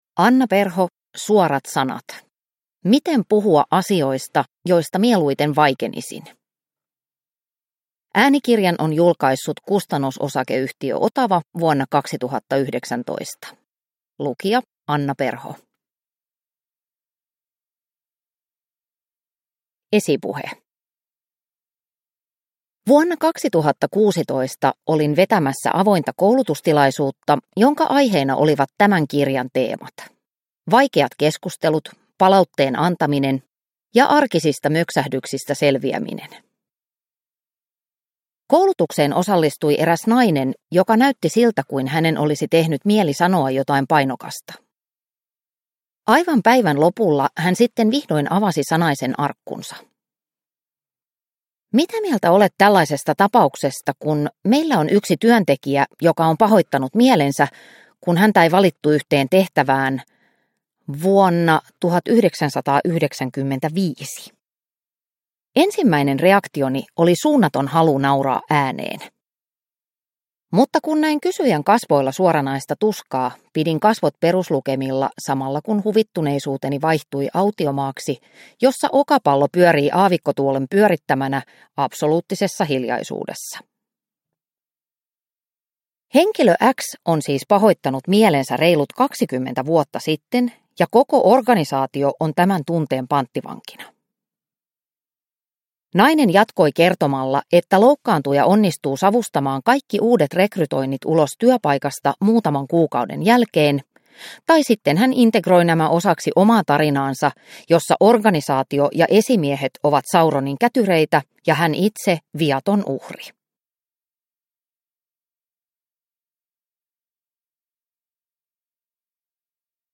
Suorat sanat – Ljudbok – Laddas ner